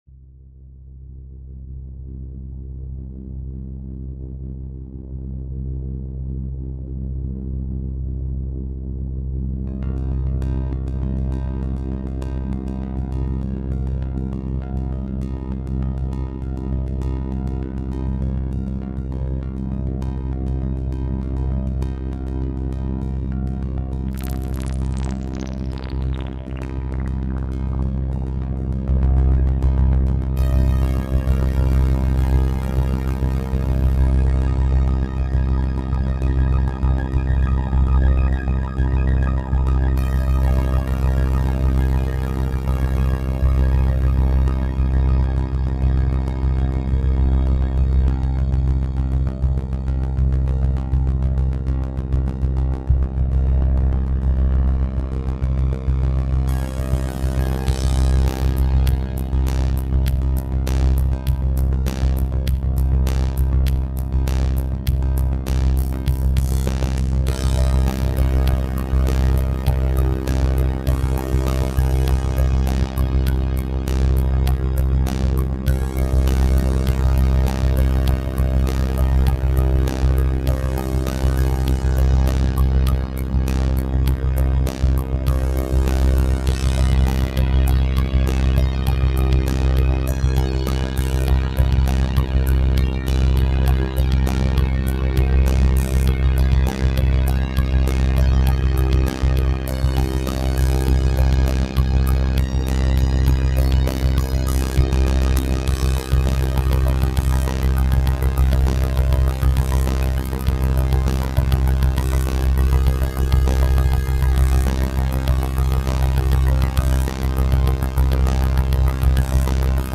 robot voice.mp3